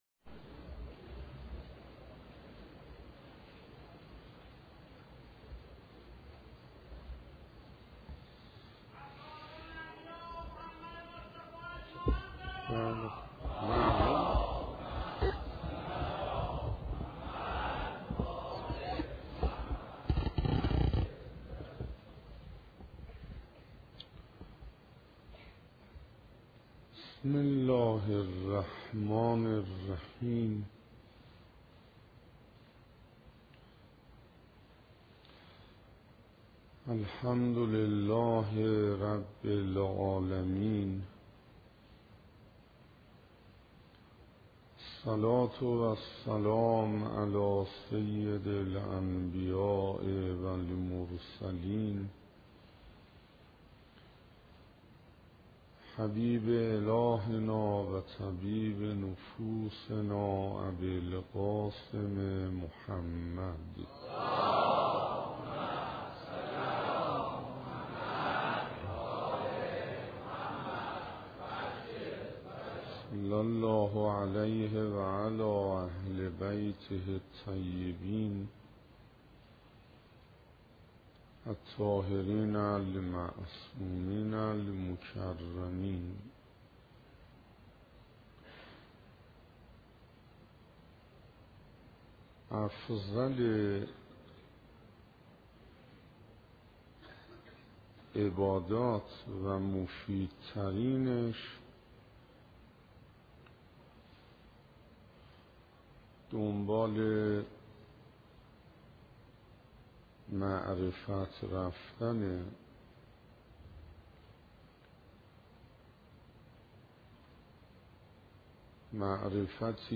دانلود نوزدهمین جلسه از مباحث «نور معرفت» در کلام حجت الاسلام استاد حسین انصاریان